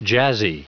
Prononciation du mot jazzy en anglais (fichier audio)
Prononciation du mot : jazzy